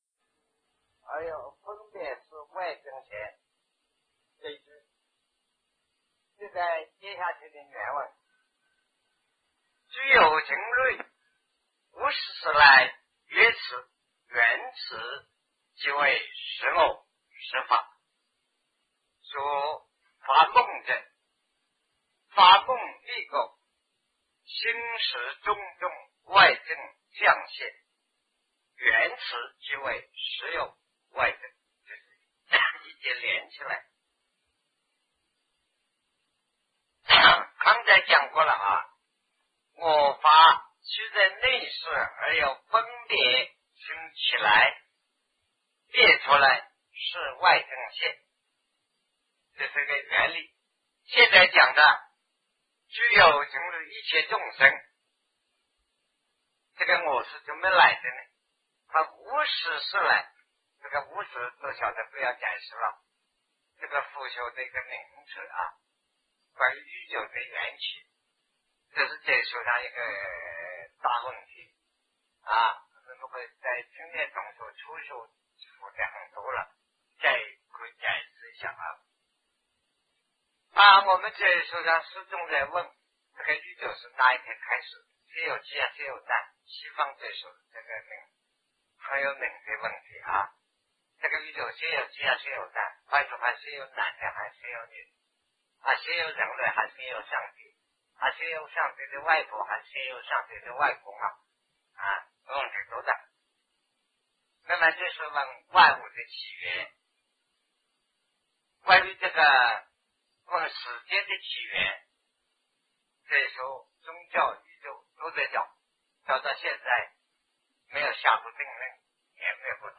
人为何有我的执着 南师讲唯识与中观（1981于台湾050(上)